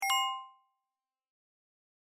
recibir_mensaje.mp3